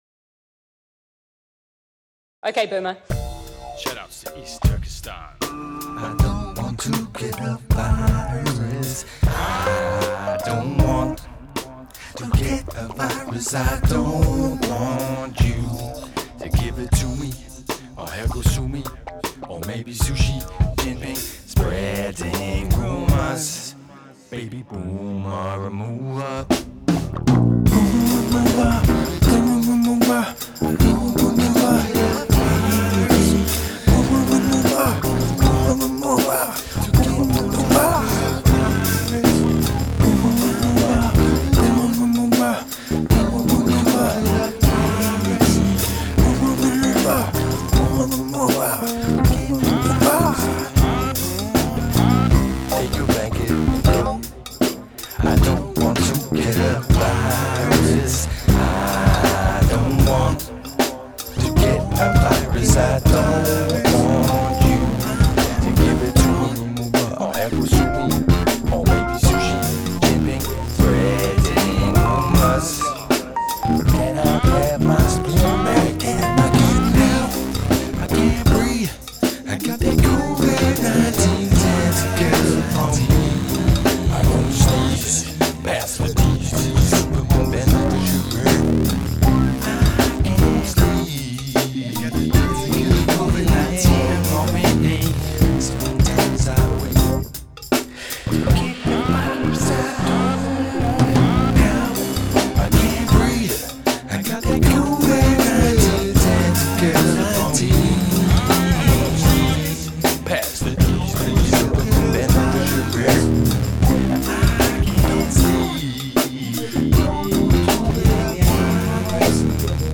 a groovy blues-funk dirge
done as an instrumental
It contains a Tuvan throat singing sample